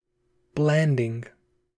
Blanding (/ˈblændɪŋ/
En-us-blanding.oga.mp3